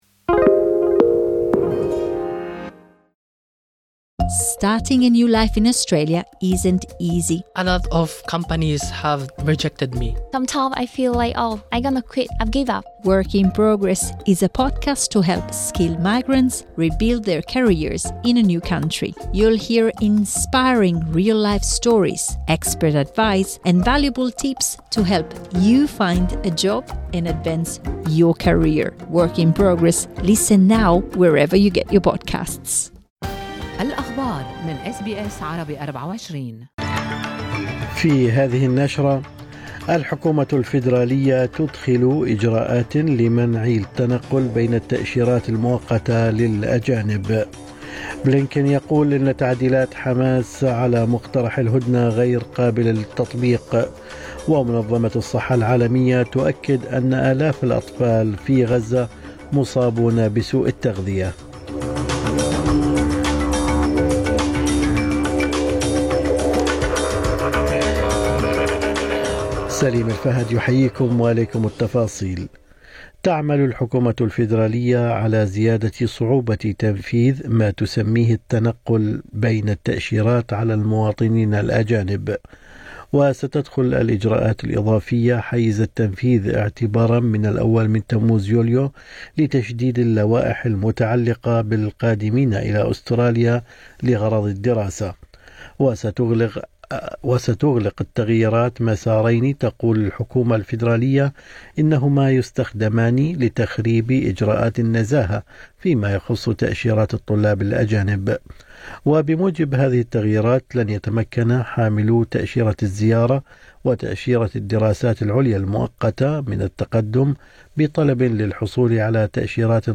نشرة أخبار الصباح 13/6/2024